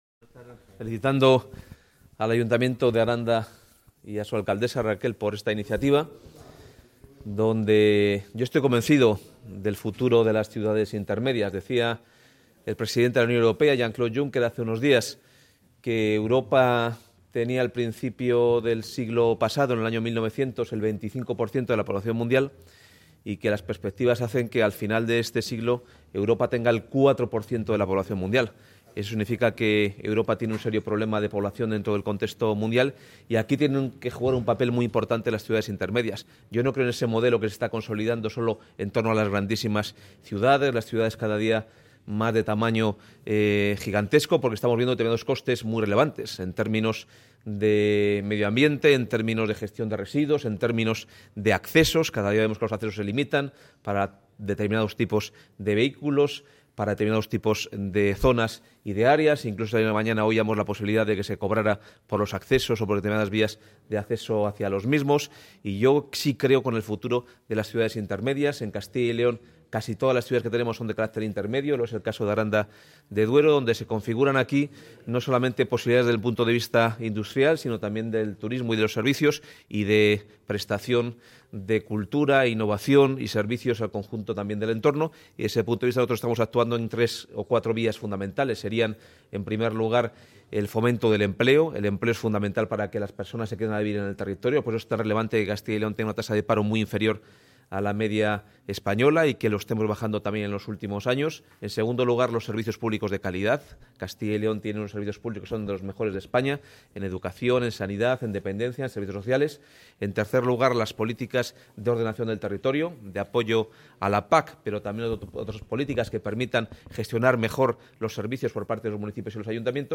Consejero de Empleo.
El consejero de Empleo, Carlos Fernández Carriedo, ha participado en la II jornada ‘Ciudades intermedias de Castilla y León. La despoblación: un reto y una oportunidad’ celebrada en Aranda de Duero, en Burgos, donde ha explicado que el impulso y el desarrollo de las políticas económicas y de empleo, el desarrollo de las políticas sociales a través de garantizar servicios públicos de calidad, las políticas territoriales y las políticas de conciliación, igualdad de género y apoyo a la familia son las bases sobre las que la Junta de Castilla y León trabaja para fijar la población en el territorio